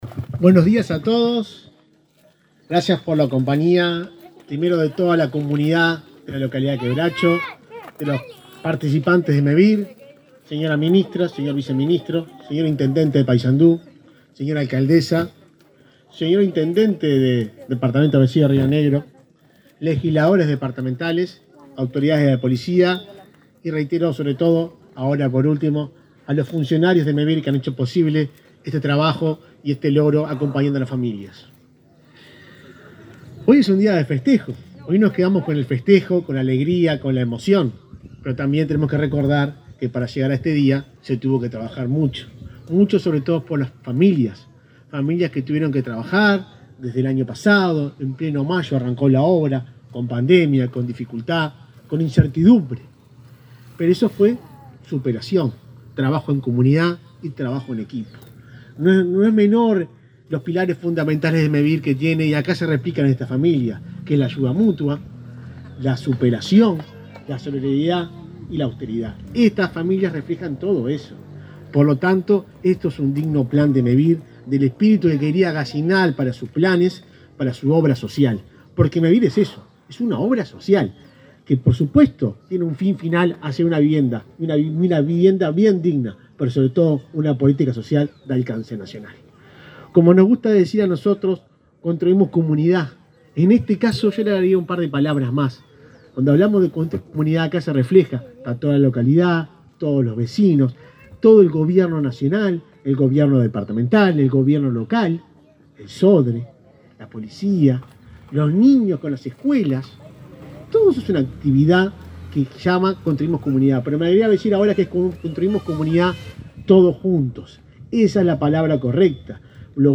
Conferencia de prensa de Mevir por la inauguración de complejo habitacional en Paysandú